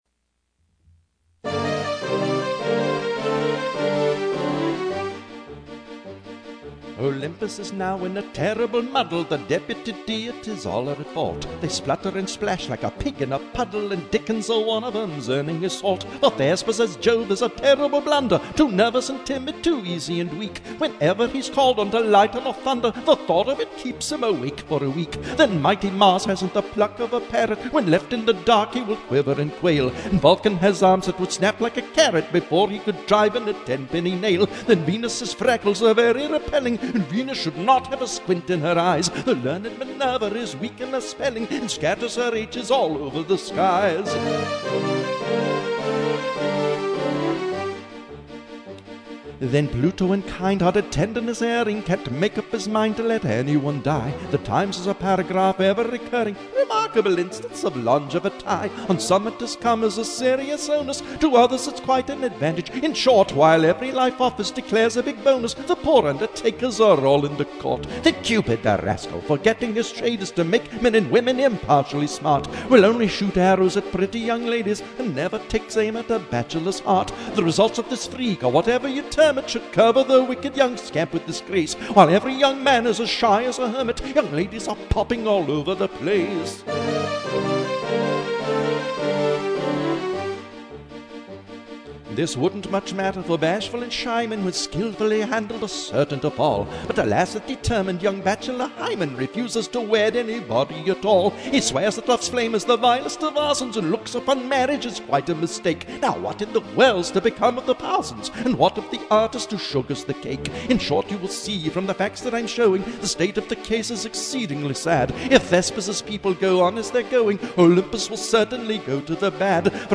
with vocals by the composer